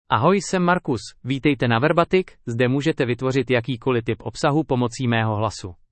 Marcus — Male Czech (Czech Republic) AI Voice | TTS, Voice Cloning & Video | Verbatik AI
MaleCzech (Czech Republic)
Marcus is a male AI voice for Czech (Czech Republic).
Voice sample
Listen to Marcus's male Czech voice.
Marcus delivers clear pronunciation with authentic Czech Republic Czech intonation, making your content sound professionally produced.